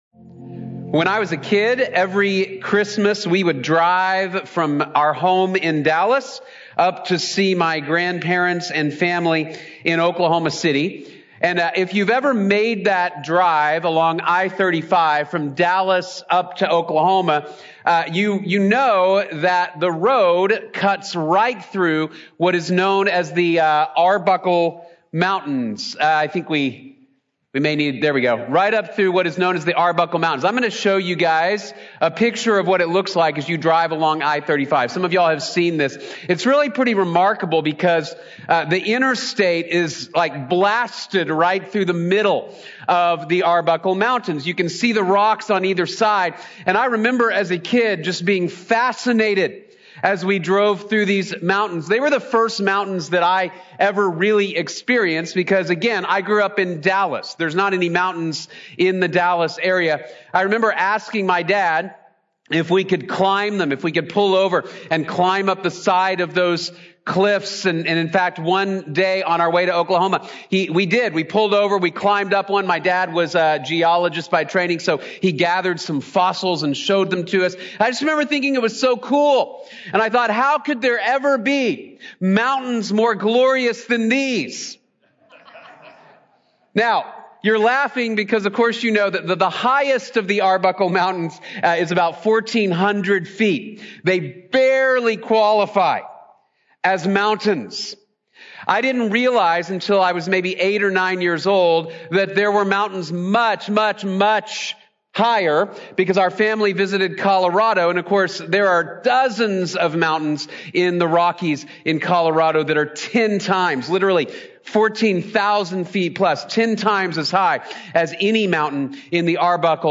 Jesus is Better | Sermon | Grace Bible Church